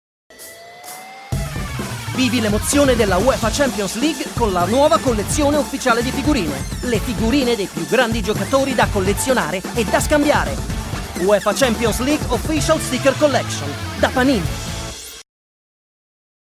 Male
young adult male,
guy next door,
smooth
Television Spots